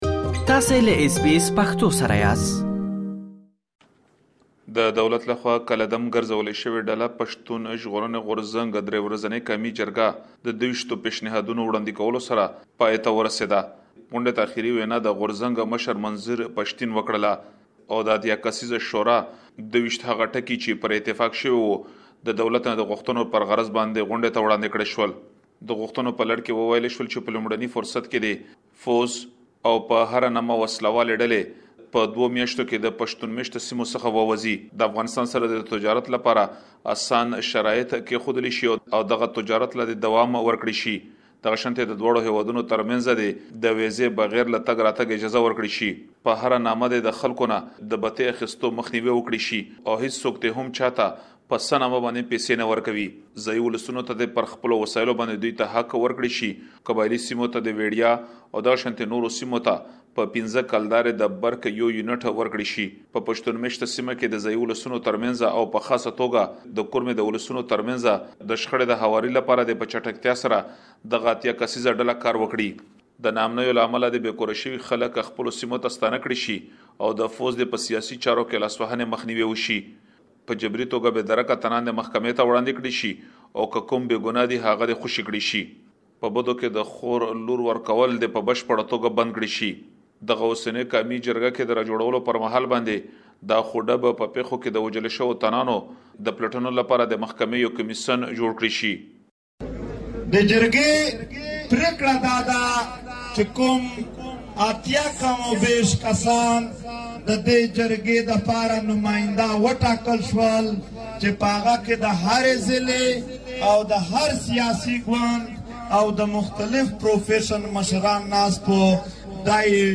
له خیبر پښتونخوا څخه د همدغې موضوع په اړه یو رپوټ را استولی دی.